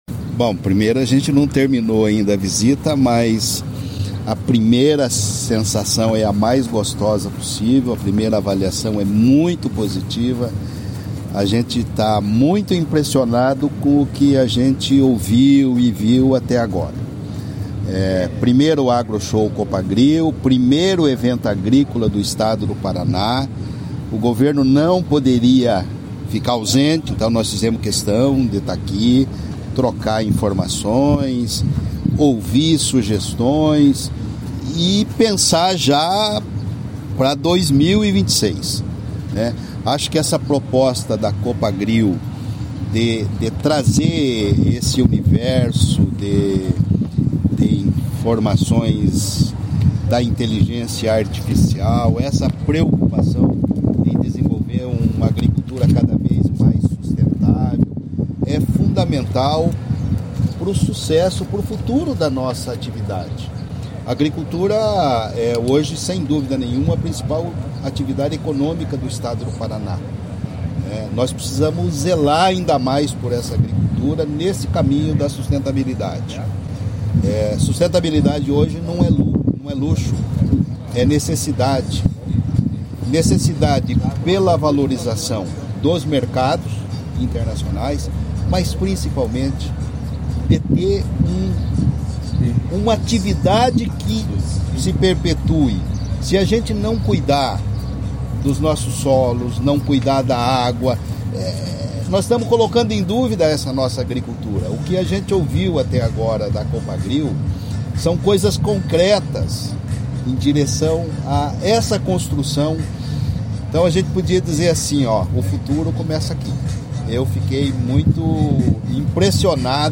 Sonora do secretário da Agricultura e do Abastecimento, Natalino Avance de Souza, sobre o Agroshow Copagril